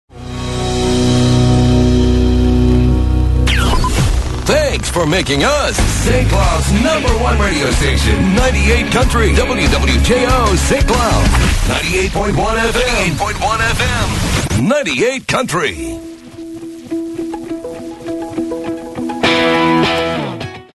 WWJO Top of the Hour Audio: